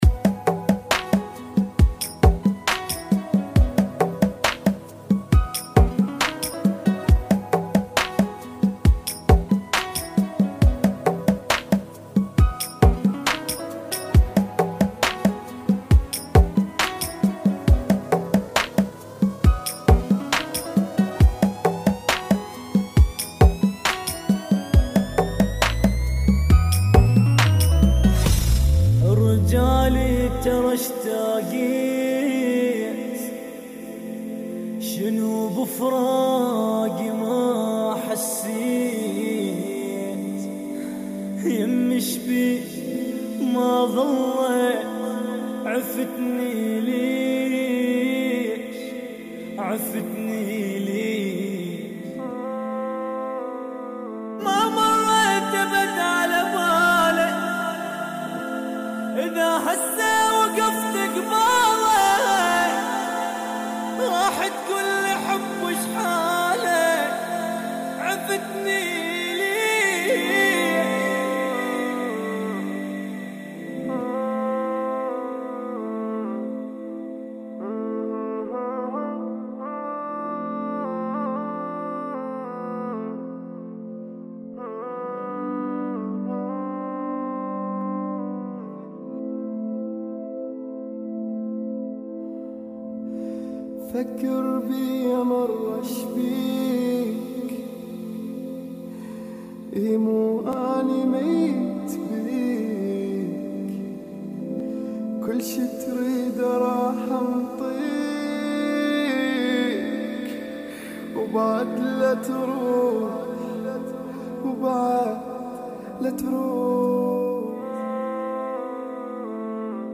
[ 108 BPM ]